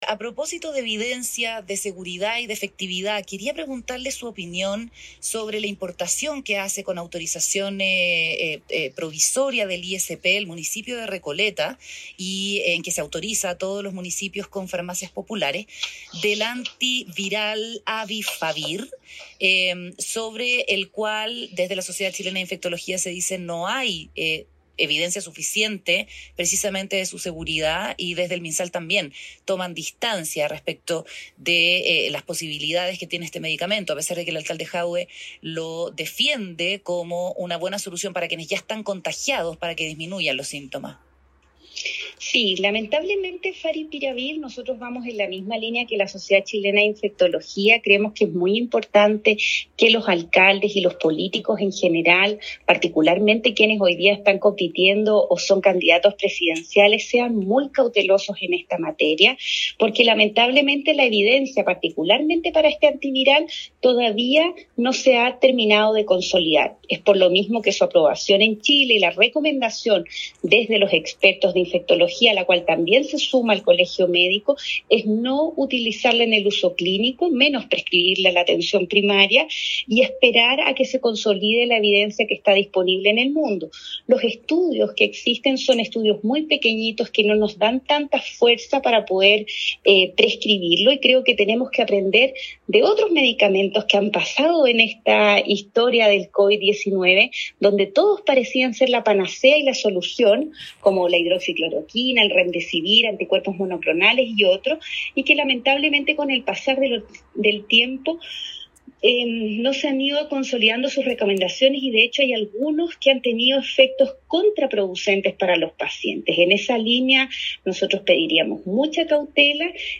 La presidenta del Colmed hizo esta crítica en declaraciones al programa Mesa Central de T13 radio.
Crédito: T13 Radio, programa Mesa Central